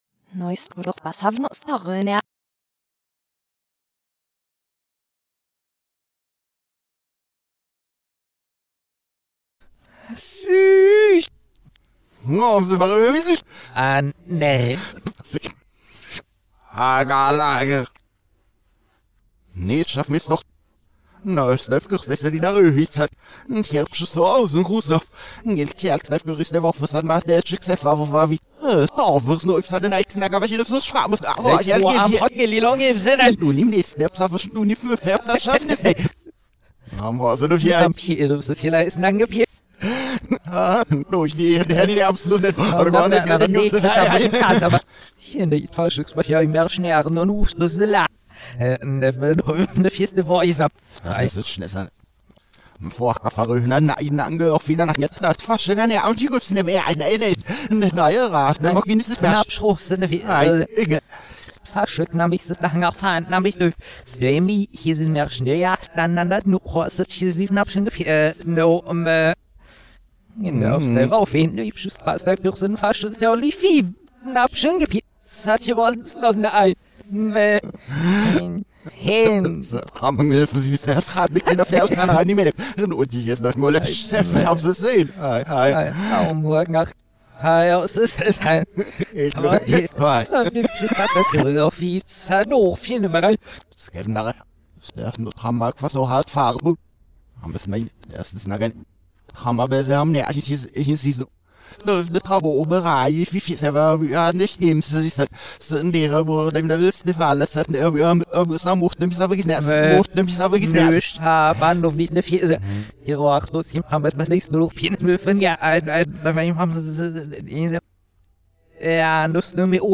Wie versprochen ist heute alles ein bisschen umgedreht. Schafft ihr es, die Folge richtig herum abzuspielen?